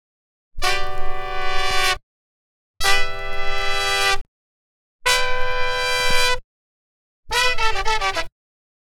DF_107_E_FUNK_HORNS_01.wav